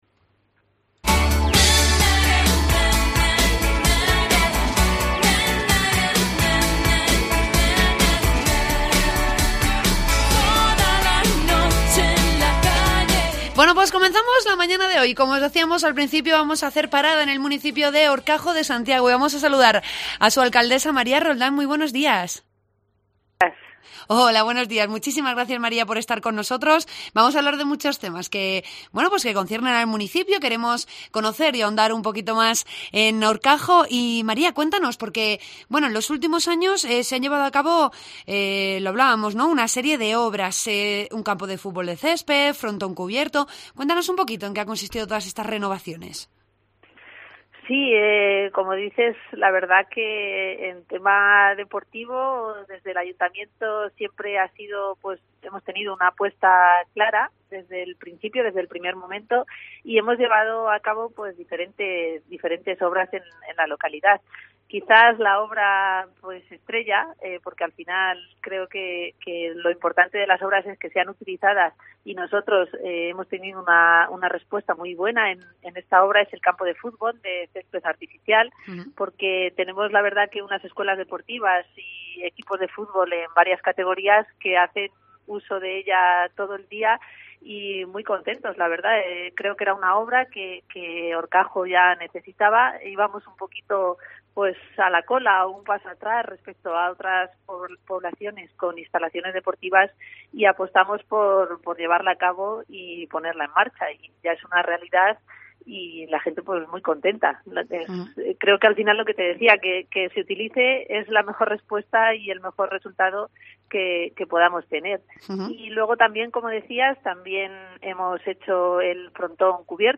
Entrevista a la alcaldesa de Horcajo de Santiago, María Roldán.